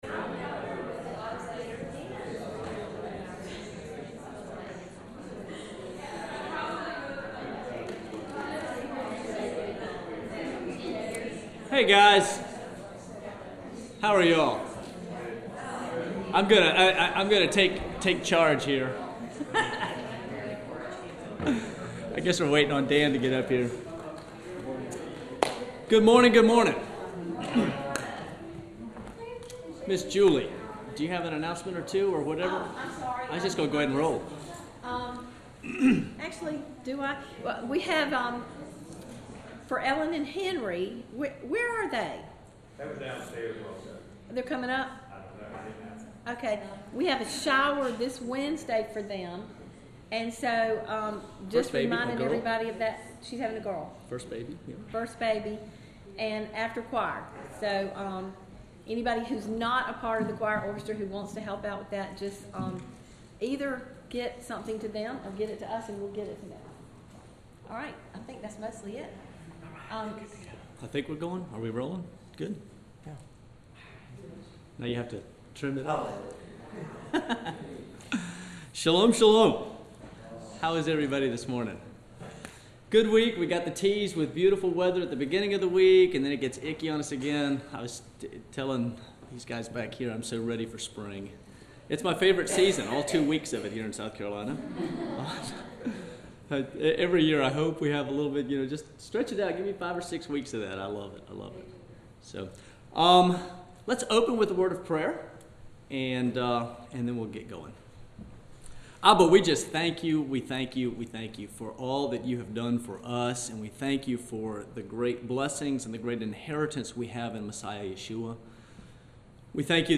The Complete Lexington Baptist Sunday School Collection